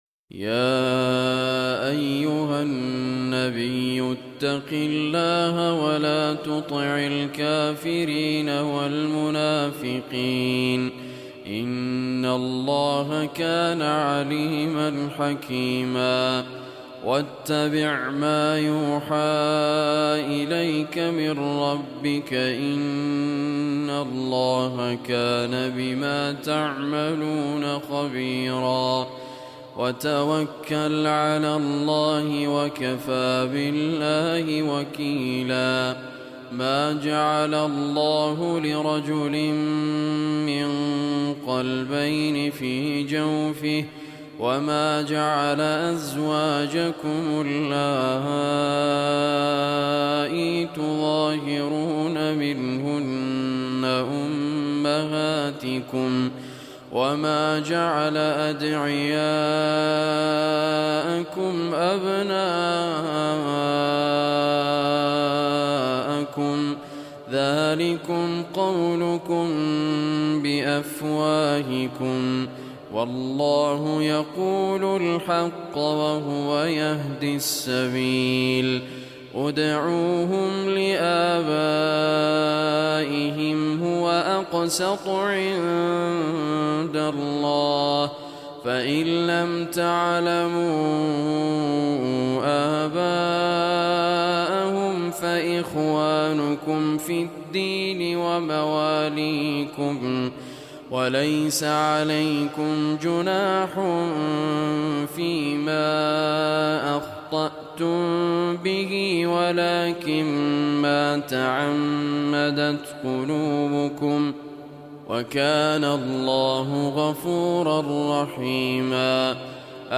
Surah Ahzab MP3 Recitation by Raad Muhammad Kurdi
Surah Ahzab is 33 surah of Holy Quran. Listen or play online mp3 tilawat / recitation in arabic in the beautiful voice of Sheikh Raad Al Kurdi.